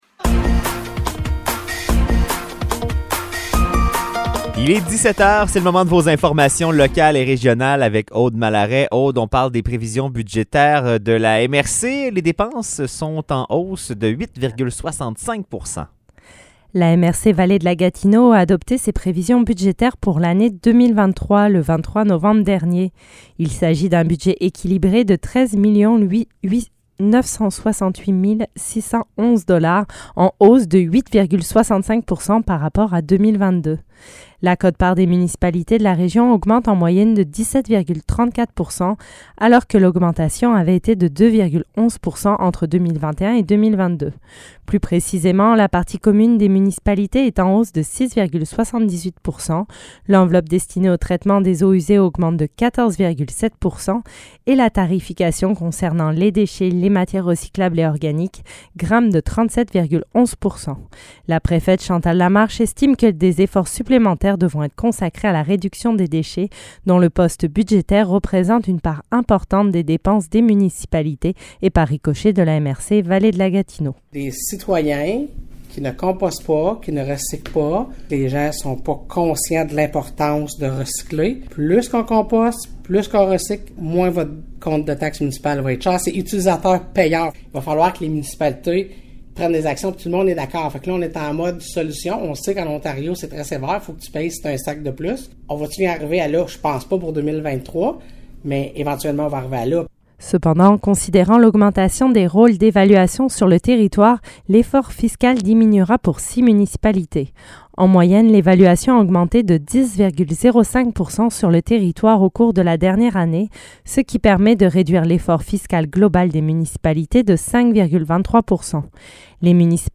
Nouvelles locales - 30 novembre 2022 - 17 h